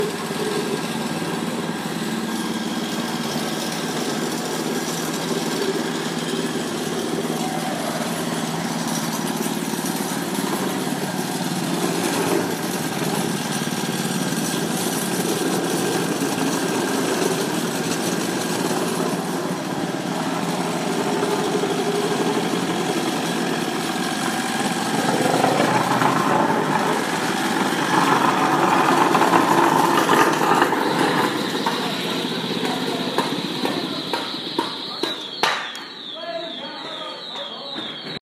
哥伦比亚 " 汽车 卡车 厢式车 颠簸的大土路到村子里的金属异响1 +减速结束
描述：汽车卡车范int驾驶颠簸大土路到村庄金属拨浪鼓1 +减速结束
标签： 坑坑洼洼 卡车 汽车 INT 驾驶面包车
声道立体声